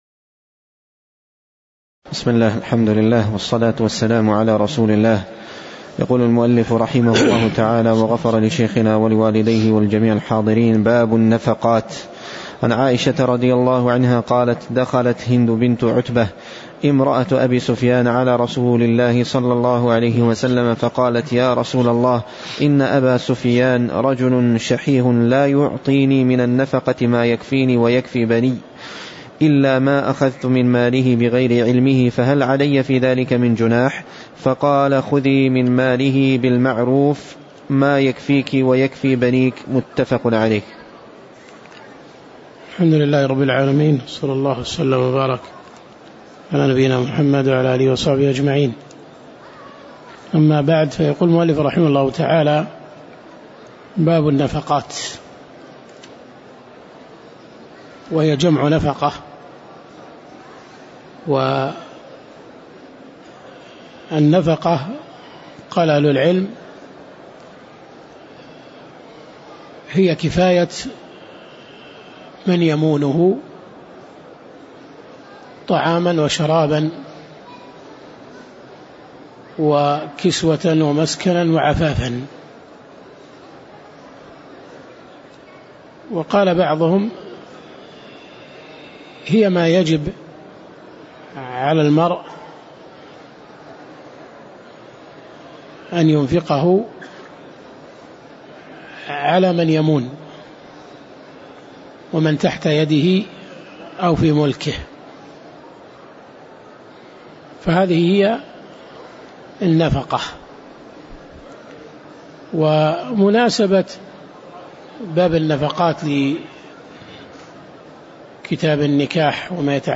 تاريخ النشر ١٠ ربيع الأول ١٤٣٩ هـ المكان: المسجد النبوي الشيخ